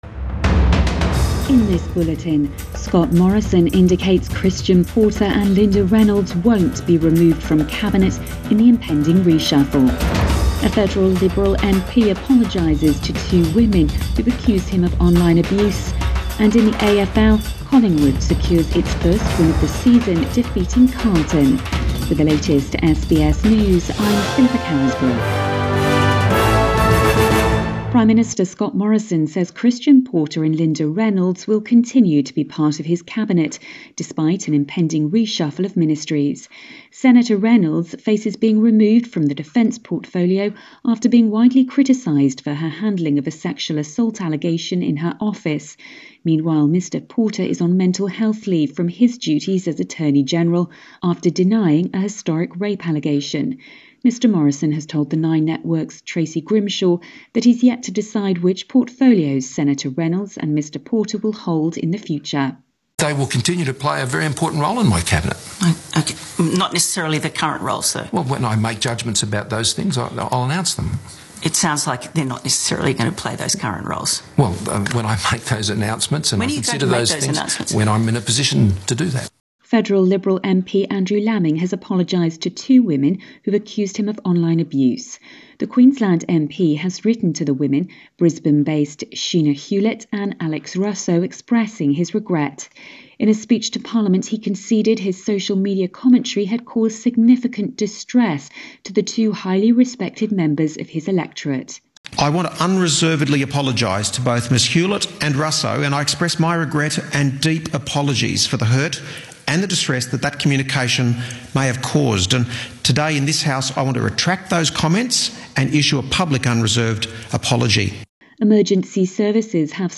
AM bulletin 26 March 2021